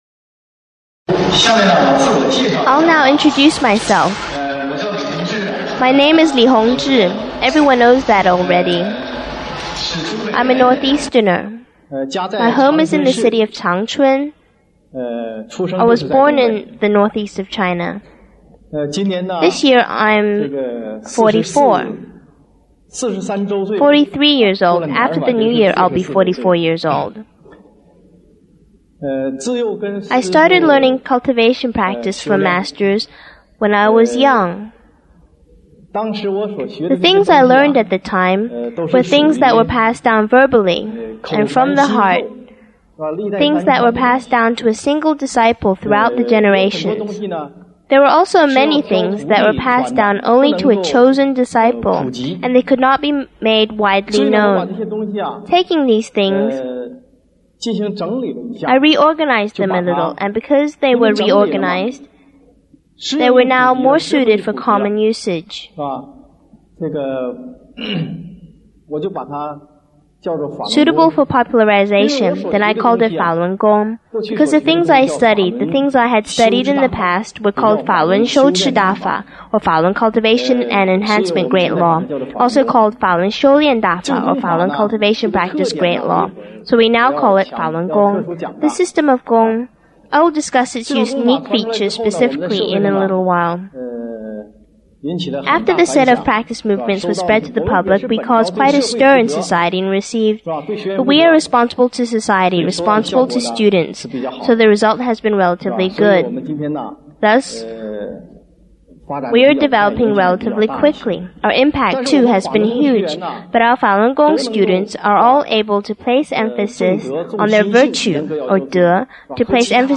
Lecture 1
En-1-Lecture.mp3